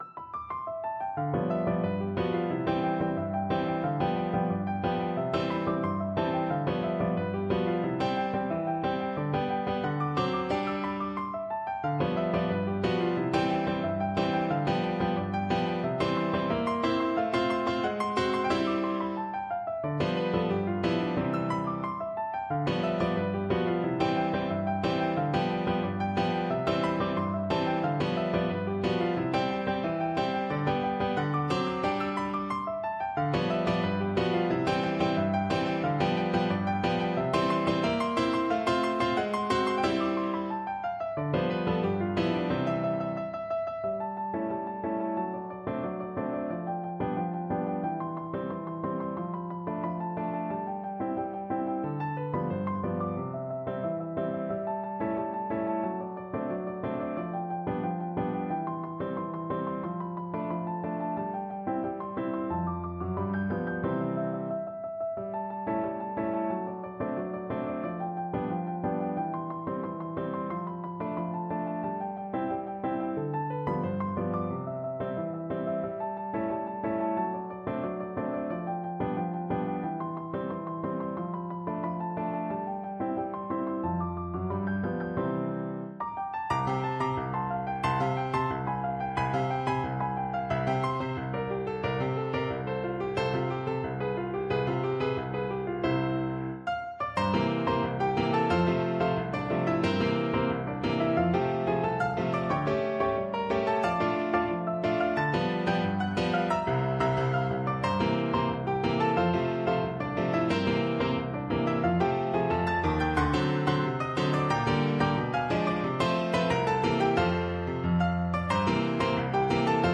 No parts available for this pieces as it is for solo piano.
C major (Sounding Pitch) (View more C major Music for Piano )
2/4 (View more 2/4 Music)
Instrument:
Piano  (View more Advanced Piano Music)
Classical (View more Classical Piano Music)
Brazilian